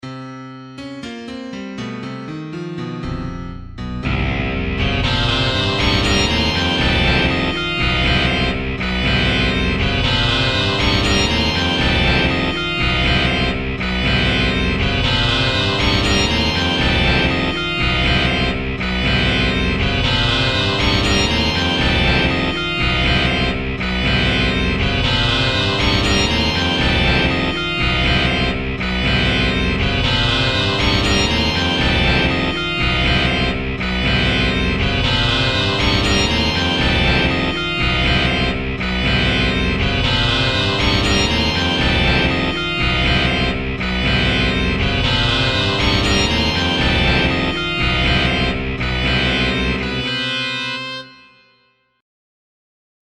• Качество: 128, Stereo
без слов
необычные
experimental
орган